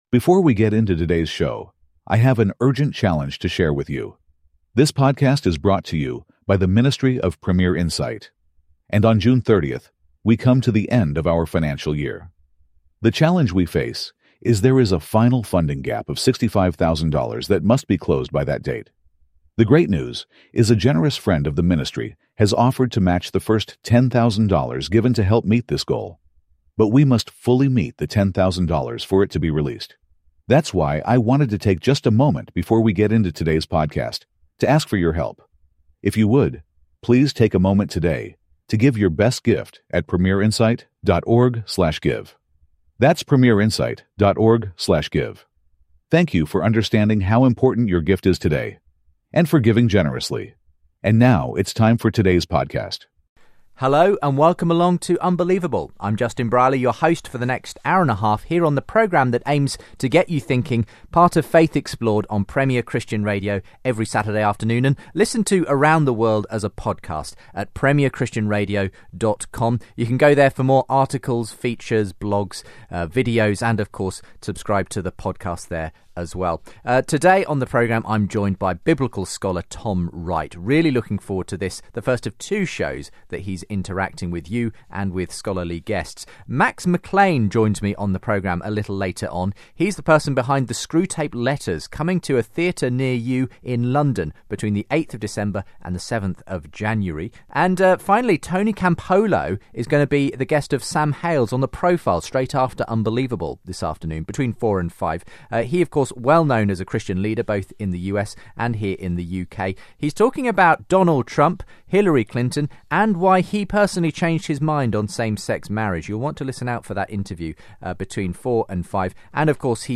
He answers a variety of questions sent in by both sceptical and Christian listeners. Topics include John's gospel, the resurrection, innerancy and hell.